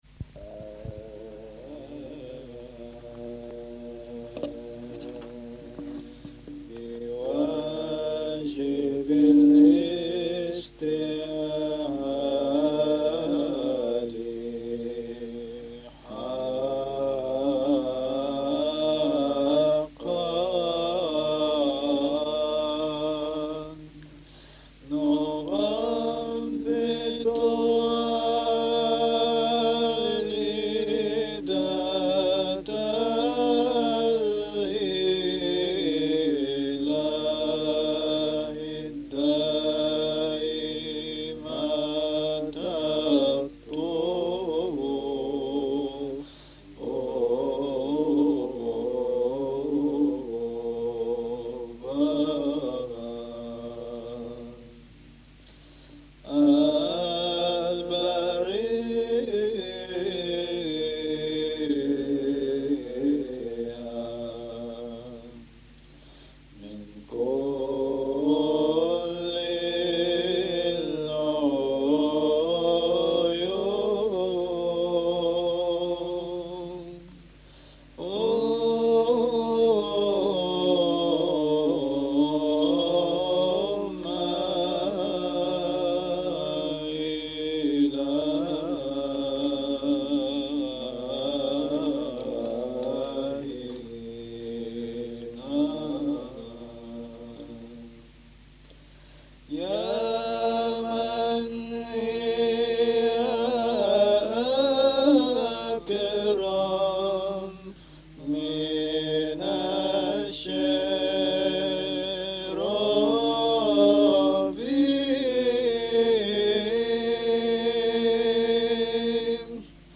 У прилогу достављамо јавности аудио-снимак црквеног појања Митрополита Павла и Владике Андреја 1987. године у Солуну, када су два будућа епископа, пријатеља заједно студирали на Солунском теолошком факултету…